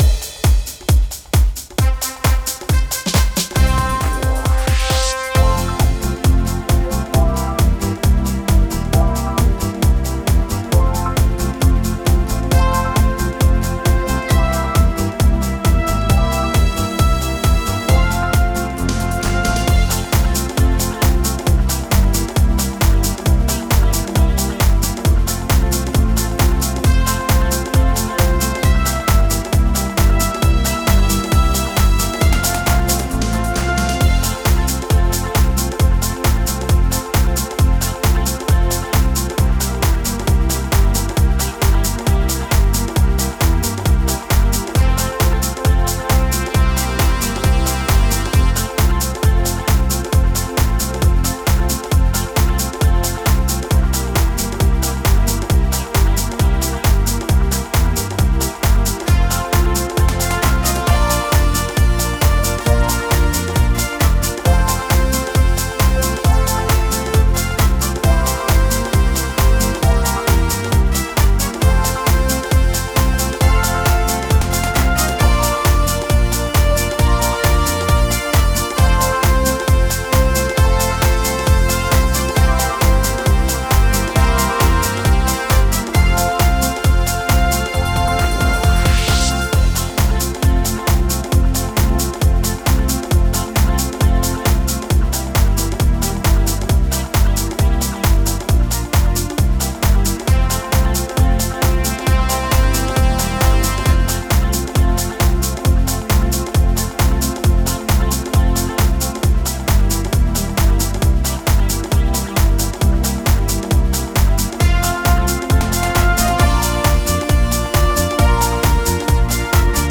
MARCHE DISCO